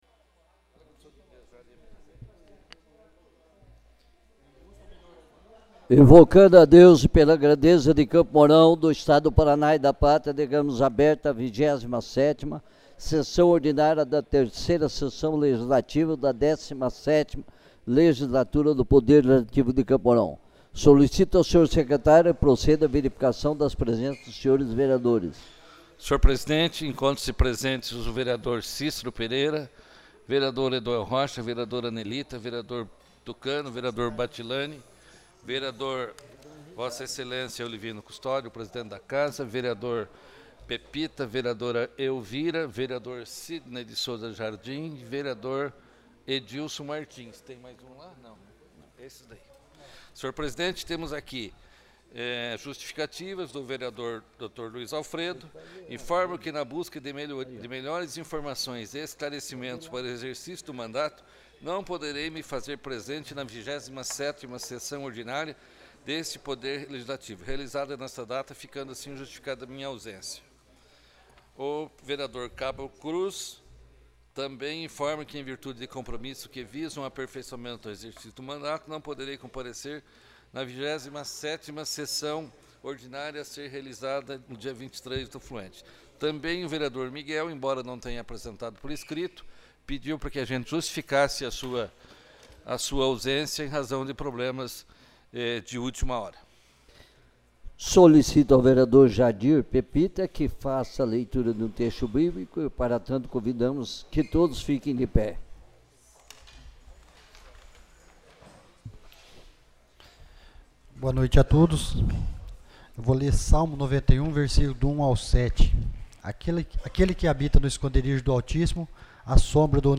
27ª Sessão Ordinária